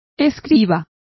Complete with pronunciation of the translation of scribes.